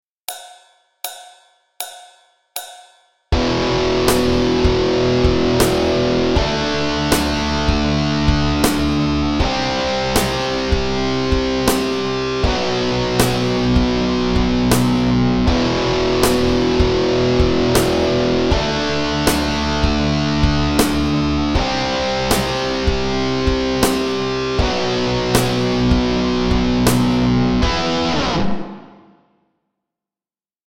The backing track to play along with
Backing_Track_Alternate_Picking_lesson.mp3